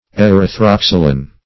Erythroxylon \Er`y*throx"y*lon\, n. [NL., from Gr.